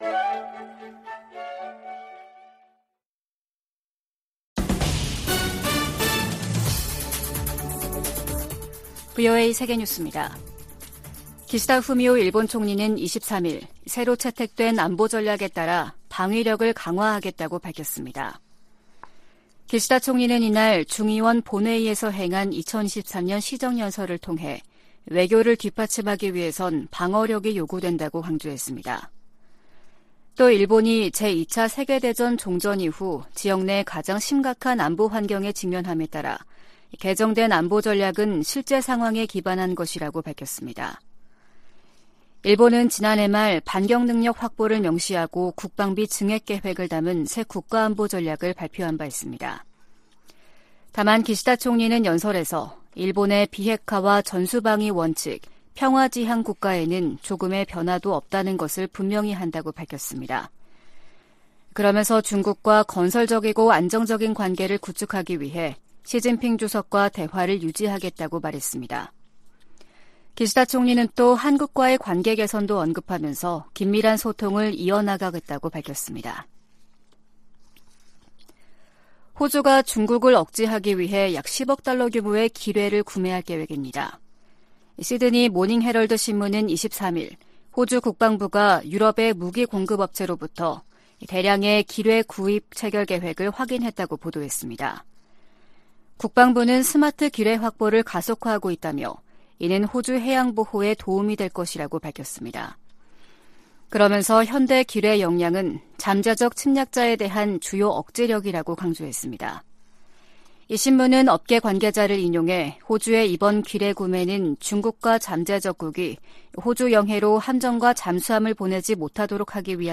VOA 한국어 아침 뉴스 프로그램 '워싱턴 뉴스 광장' 2023년 1월 24일 방송입니다. 백악관이 북한과 러시아 용병그룹 간 무기 거래를 중단할 것을 촉구하고, 유엔 안보리 차원의 조치도 모색할 것이라고 밝혔습니다. 미 태평양공군은 한국 공군과 정기적으로 훈련을 하고 있으며, 인도태평양의 모든 동맹, 파트너와 훈련할 새로운 기회를 찾고 있다는 점도 강조했습니다.